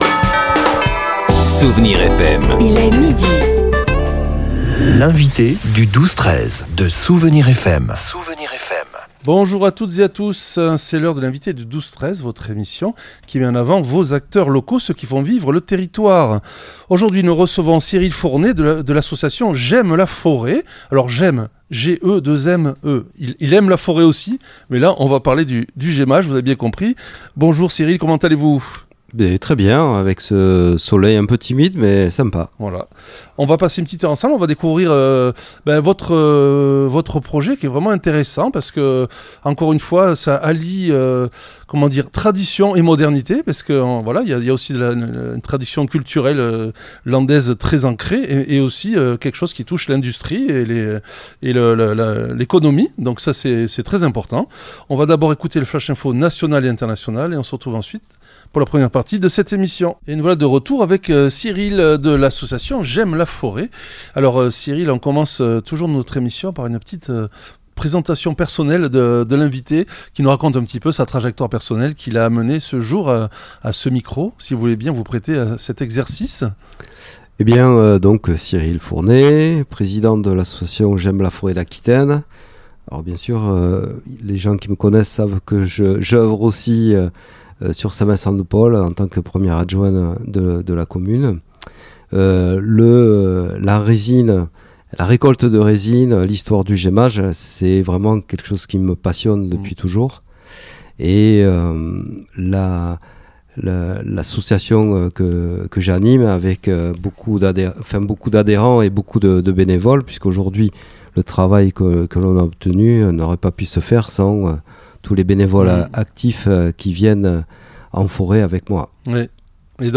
L'entretien a permis de redécouvrir les multiples visages de la résine : l'essence de térébenthine et surtout la colophane, cet « arcanson » autrefois indispensable aux instruments à cordes, qui trouve aujourd'hui sa place dans la cosmétique ou l'électronique. En collaborant avec nos voisins espagnols et portugais, ce projet vise une indépendance stratégique et une valorisation durable de la filière bois face aux enjeux climatiques.